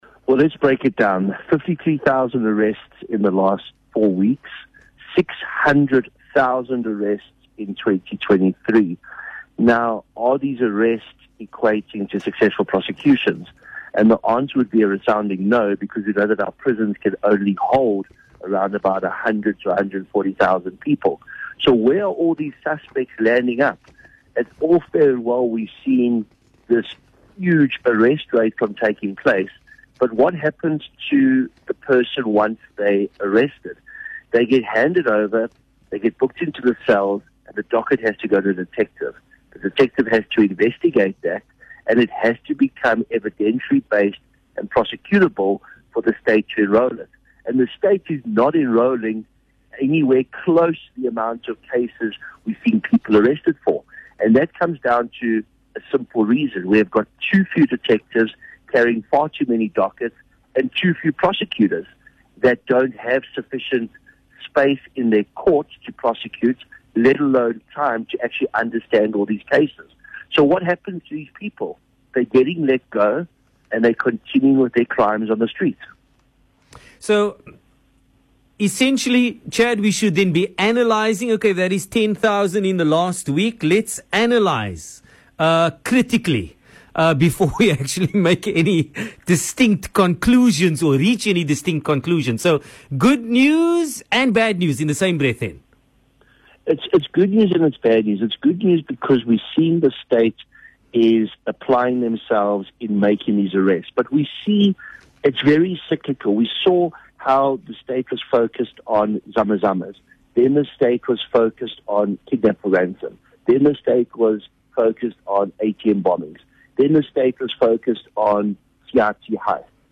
Western Cape’s No.1 Community Radio Station